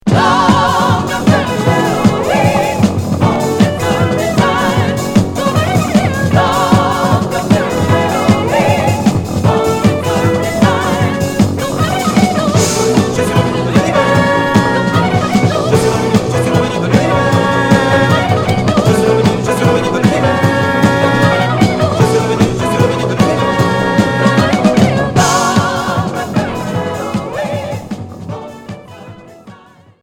Free rock